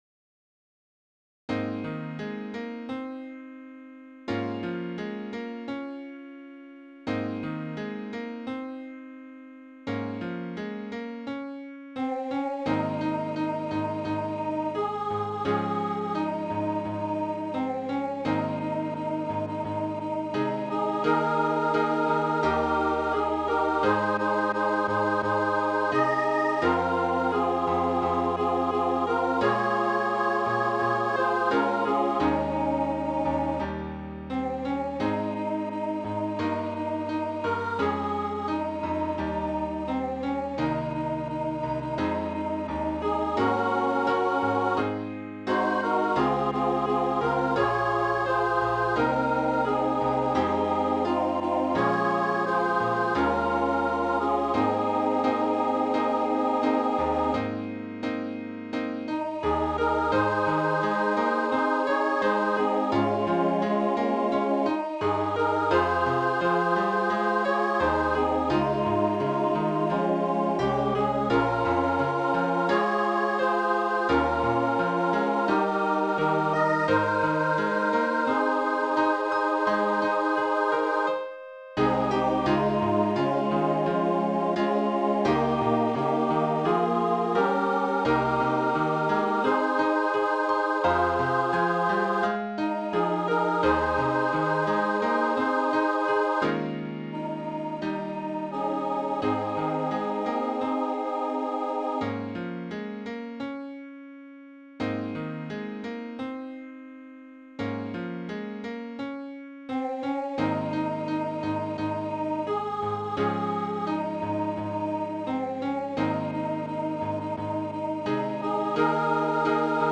Encircle Me With Love, High SSA in Ab
Voicing/Instrumentation: SSA , Trio